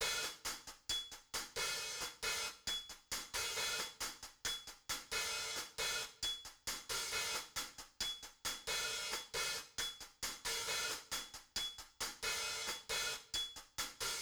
Vanilla Sky Hi Hat Loop.wav